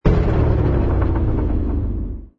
engine_li_freighter_kill.wav